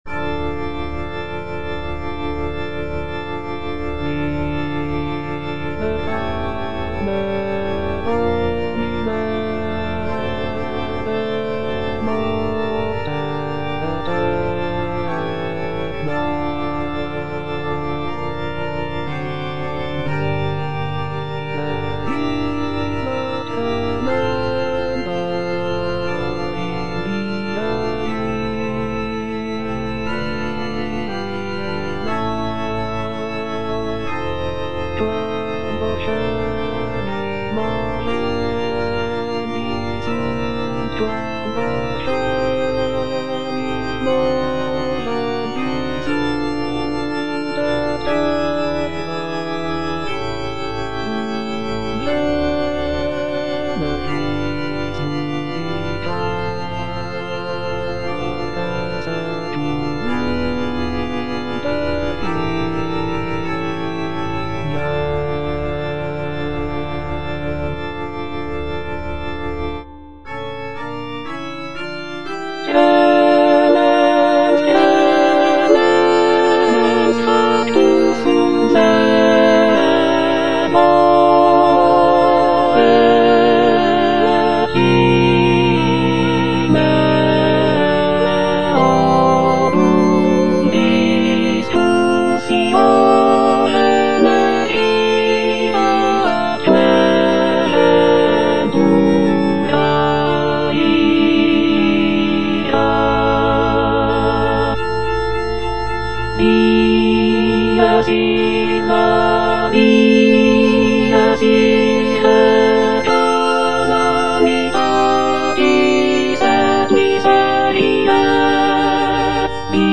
G. FAURÉ - REQUIEM OP.48 (VERSION WITH A SMALLER ORCHESTRA) Libera me - Alto (Emphasised voice and other voices) Ads stop:
This version features a reduced orchestra with only a few instrumental sections, giving the work a more chamber-like quality.